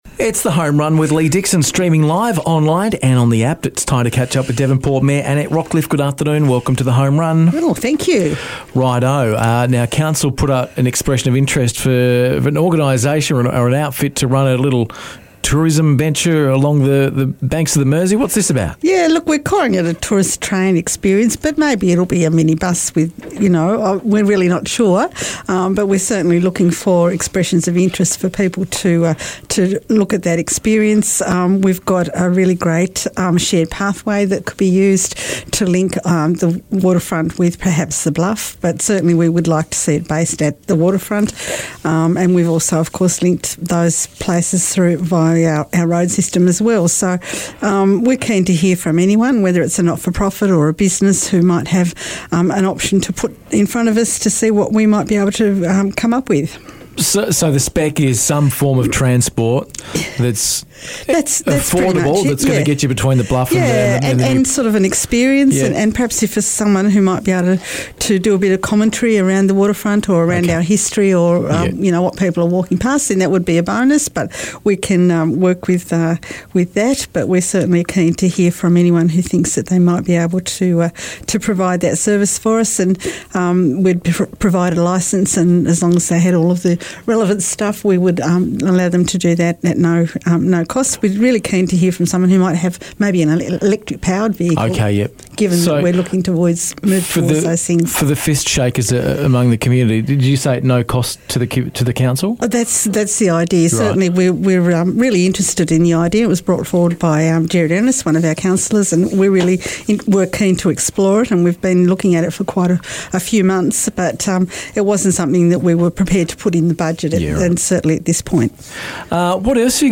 Devonport Mayor, Annette Rockliffe, dropped by The Home Run for a chat